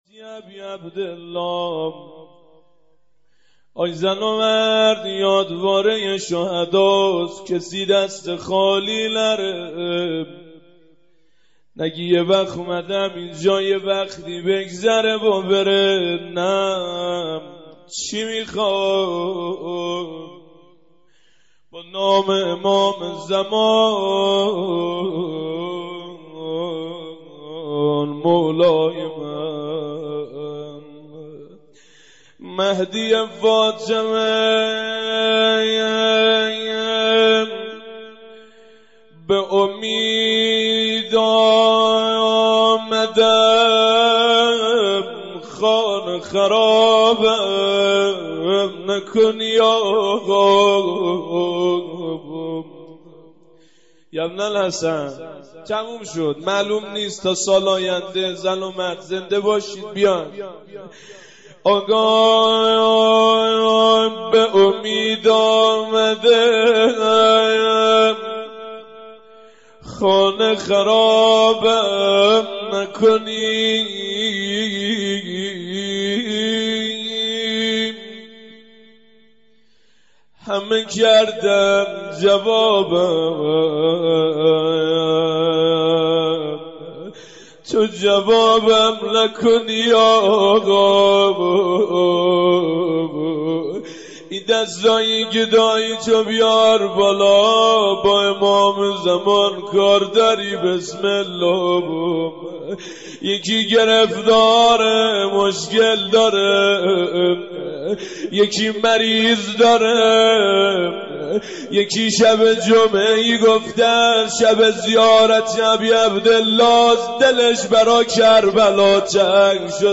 مداحی پایانی یادواره ی شهدای زنگی آباد